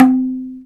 MADAL 3A.WAV